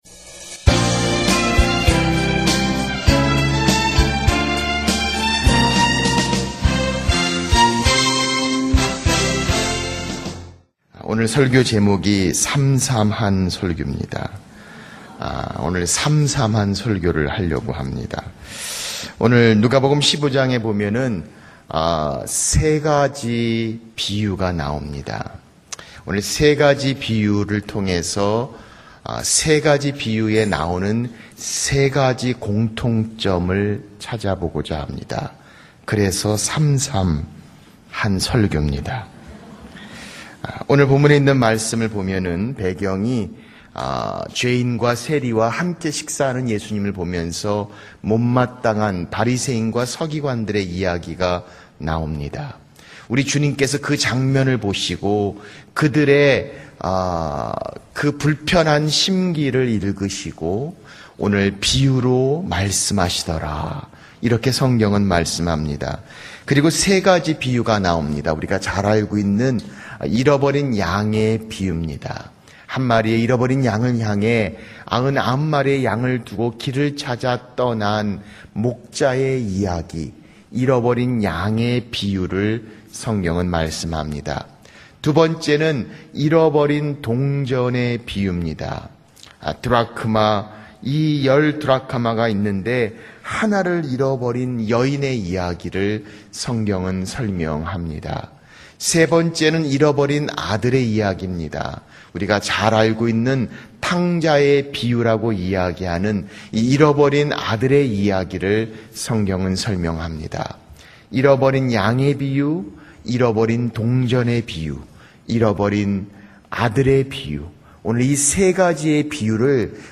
설교 | 삼삼한 설교